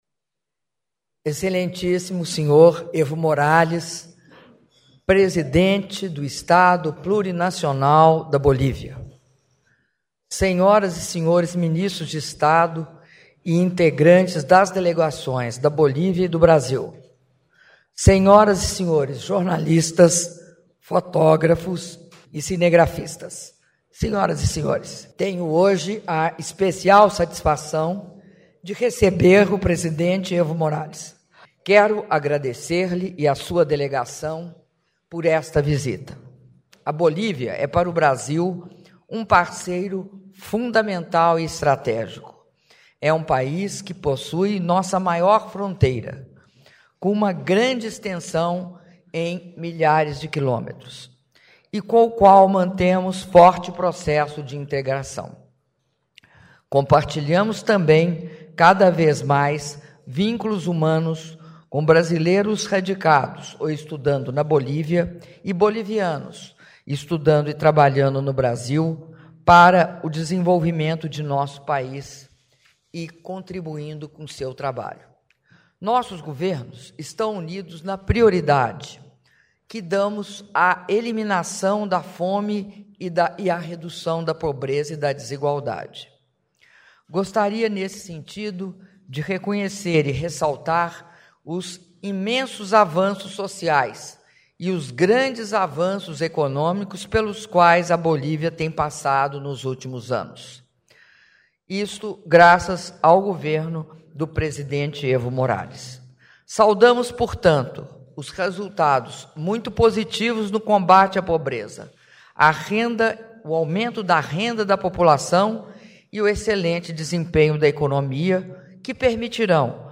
Áudio do declaração à imprensa da Presidenta da República, Dilma Rousseff, após Reunião ampliada com o presidente do Estado Plurinacional da Bolívia, Evo Morales -Brasília/DF (07min44s)